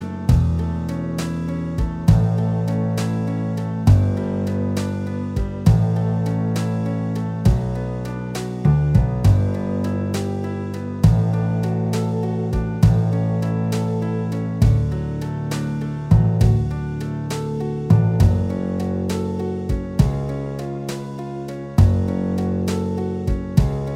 Minus Lead And Solo Guitars Rock 4:31 Buy £1.50